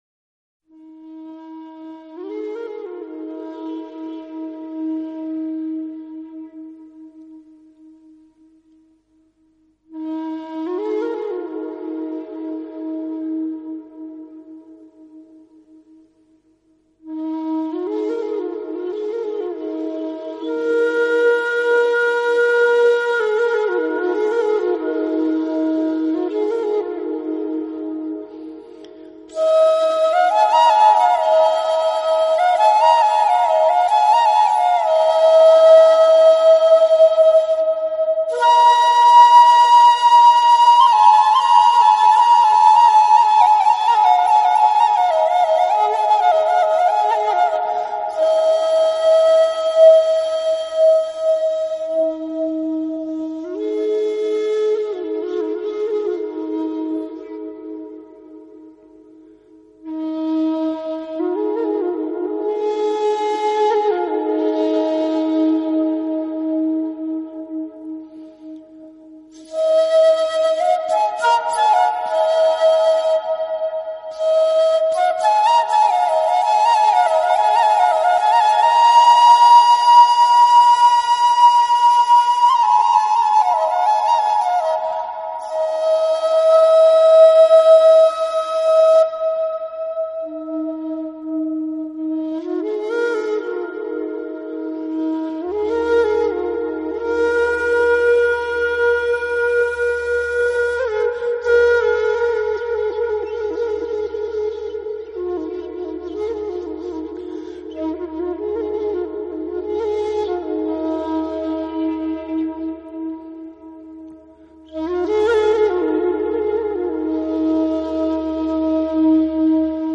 纯音乐专辑
有点类似埙，低沉而哀婉，如泣如诉。